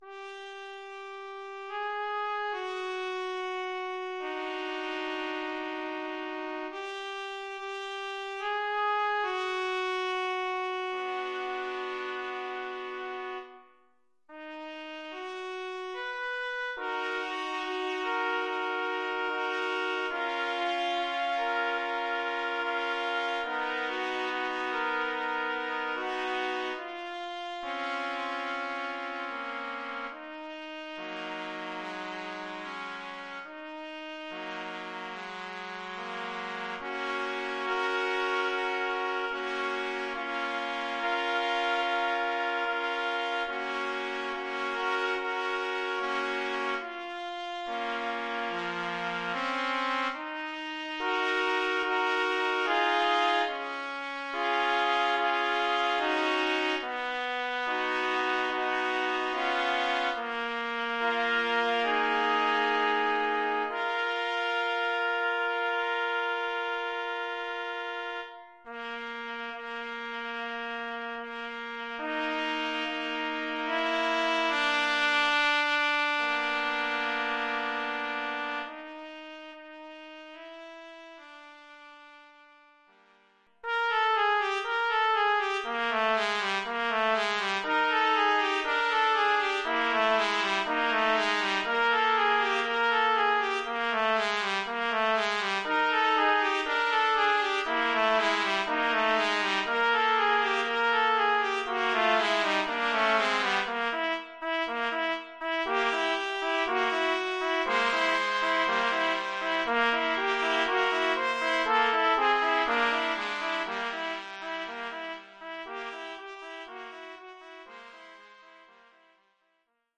Oeuvre pour trio de trompettes
(trompettes en sib 1, 2 et 3).
La pièce est structurée en deux parties.